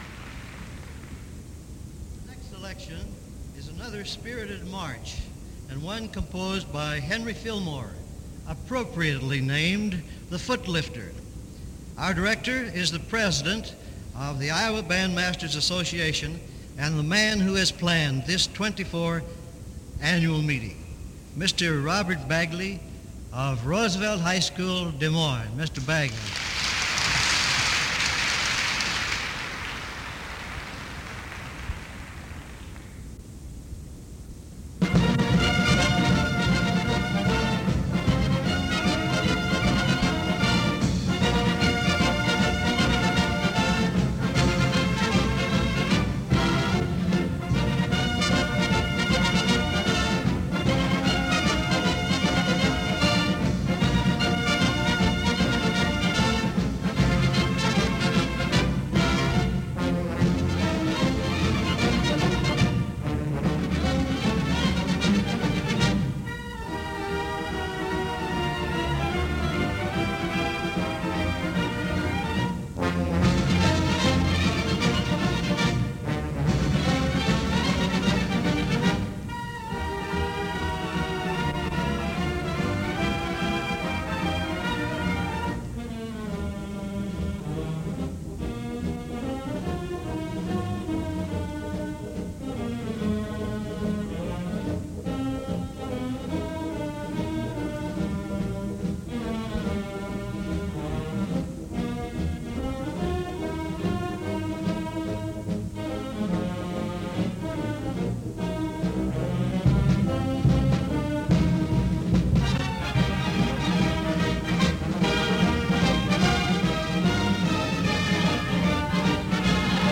These mp3 files are from a CD that was a digital transfer from a reel-to-reel tape. It is a recording of radio WHO’s broadcast of the Iowa Bandmasters Association “Directors Band” that played at the 1951 convention in Des Moines.